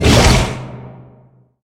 Sfx_creature_bruteshark_chase_os_08.ogg